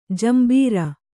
♪ jambīra